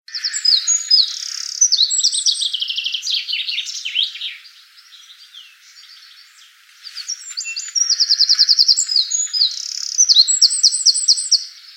European Robin (Erithacus rubecula)
Life Stage: Adult
Location or protected area: Cambridge
Condition: Wild
Robin.MP3